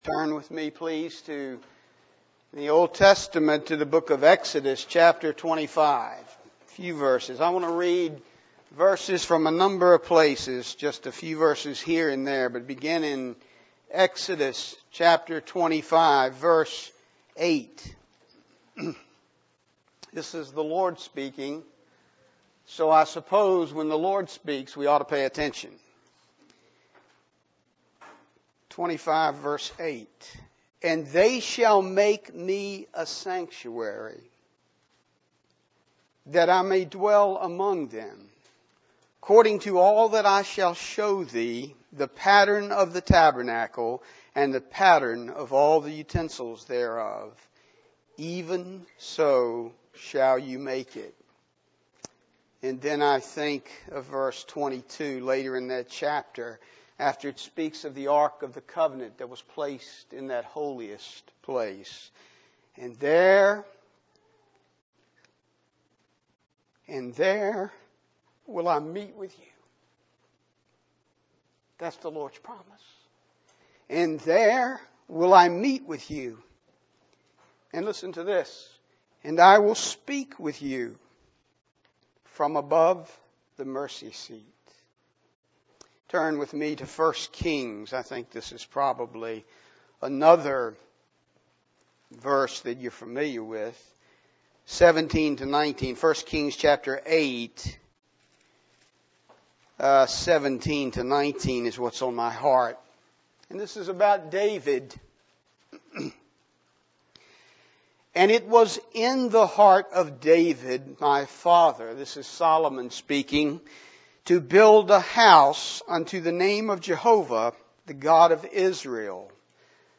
A collection of Christ focused messages published by the Christian Testimony Ministry in Richmond, VA.
Richmond, Virginia, US